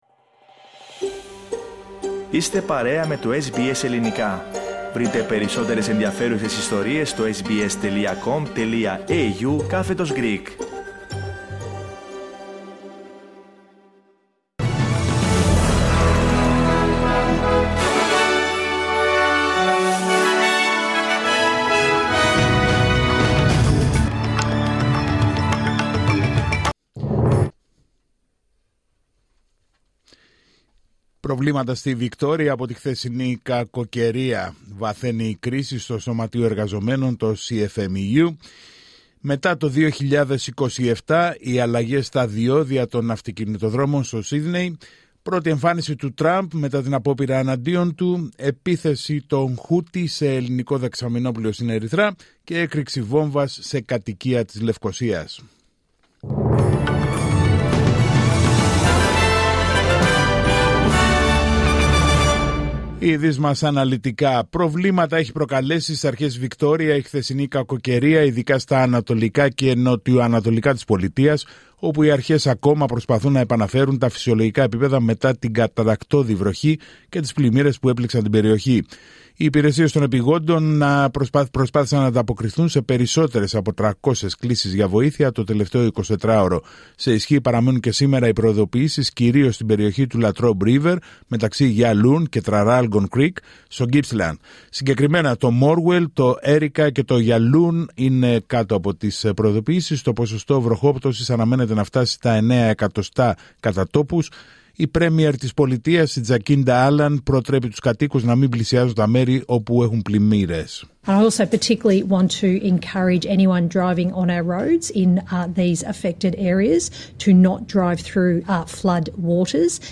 Δελτίο Ειδήσεων Τρίτη 16 Ιουλίου 2024